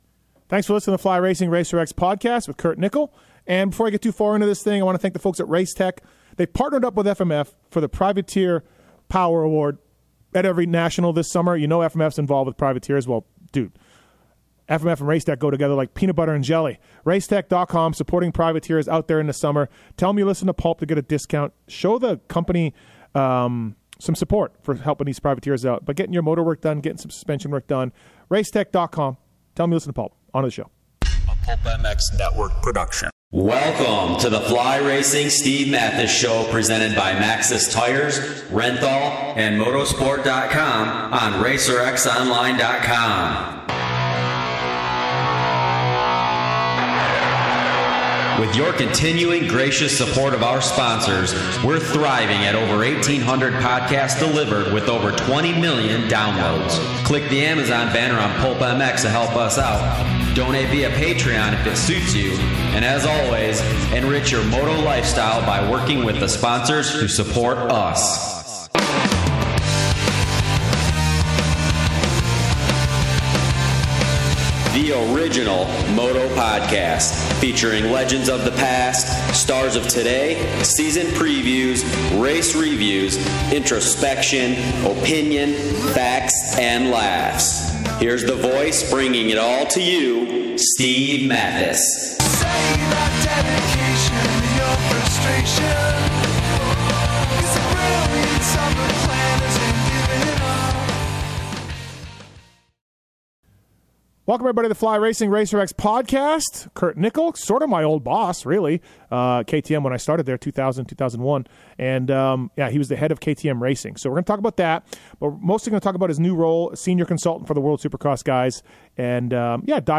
Guest: Kurt Nicoll